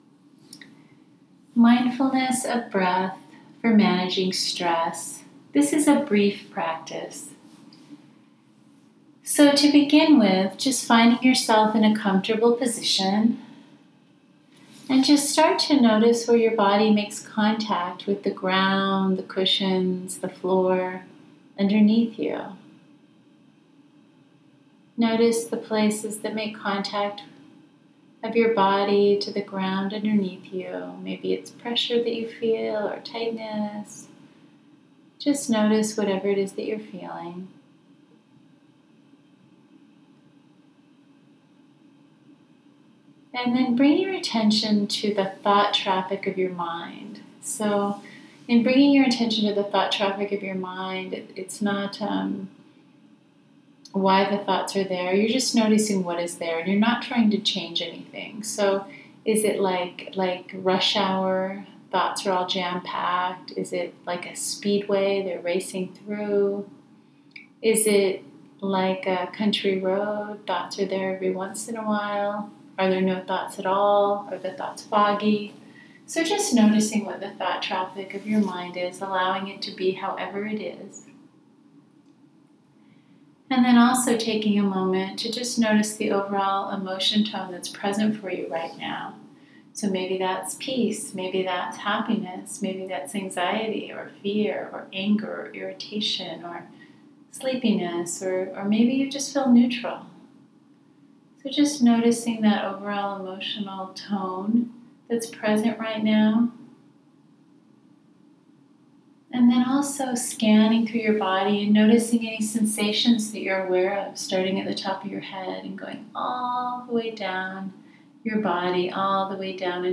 here for a free audio link for free 5-minute Mindfulness of Breath Break.